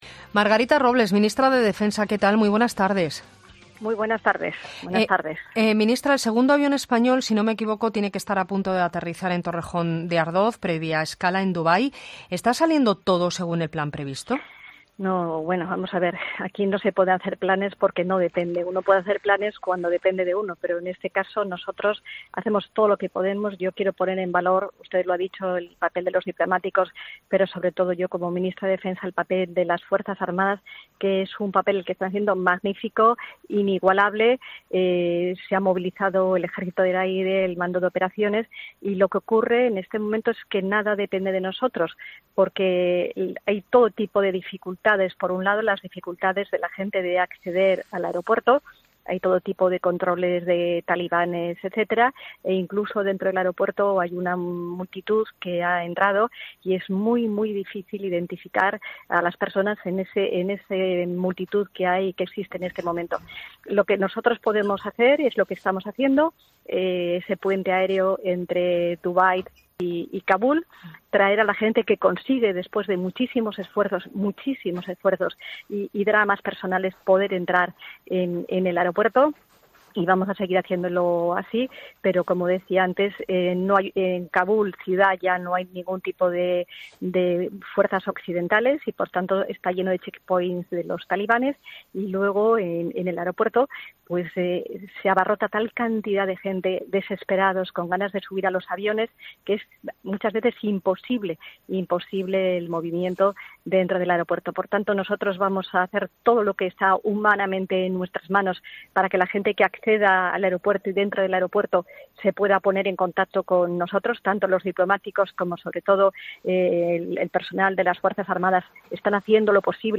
La ministra de Defensa Margarita Robles, habla sobre la situación que se vive en Afganistán